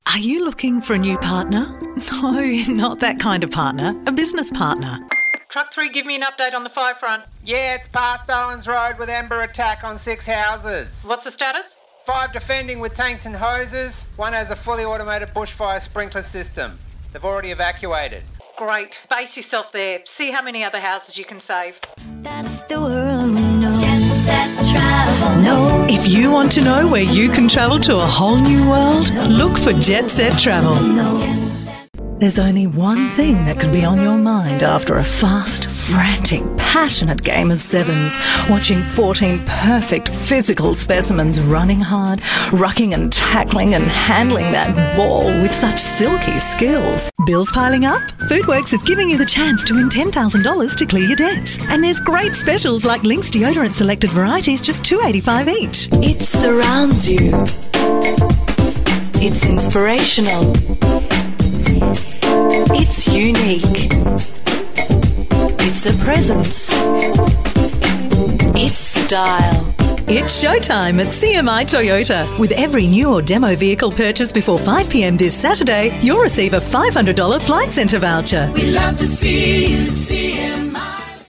Englisch (Australisch)
Warm Smooth Versatile